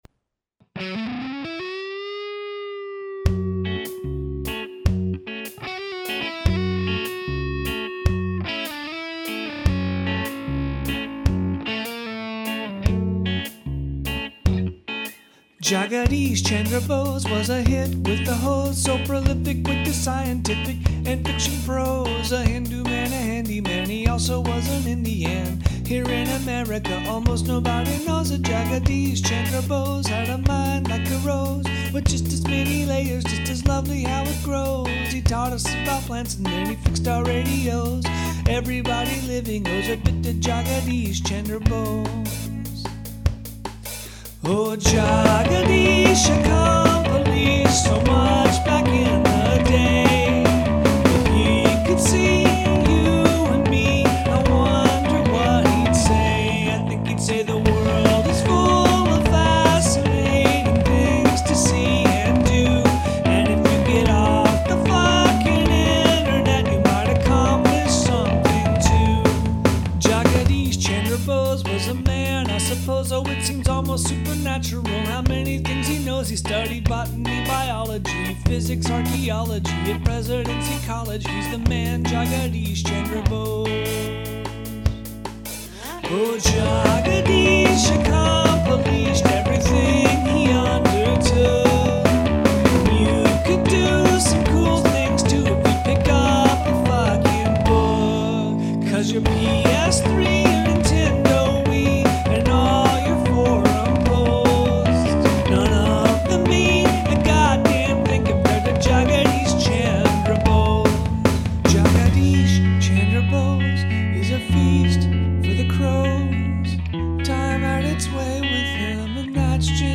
A power surge (I think) killed both my studio computer and my MOTU recorder a few weeks ago, so I recorded this one using a temporary setup that's a bit wonky in many ways. If it sounds a little off, that's why.